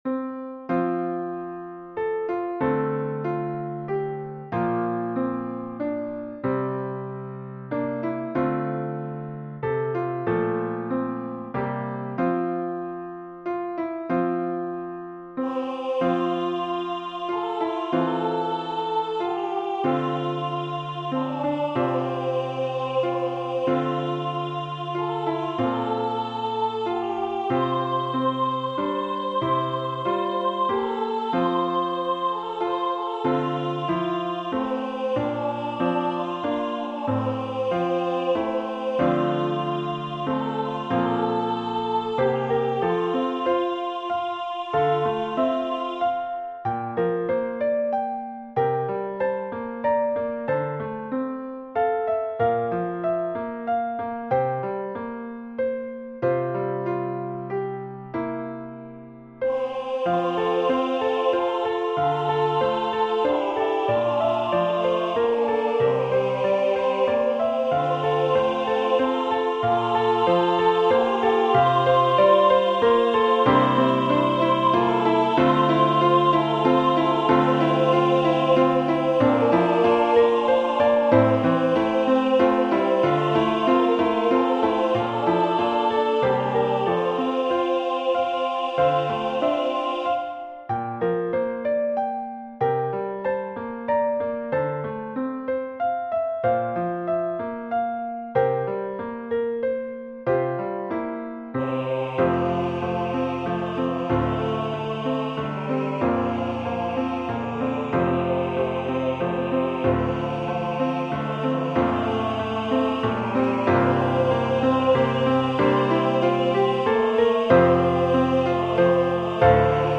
SATB
Amazing Grace Hymn #1010 SATB with Piano Accompaniment. It is a hymplicity style arrangement where the choir sings parts straight out of the hymnbook.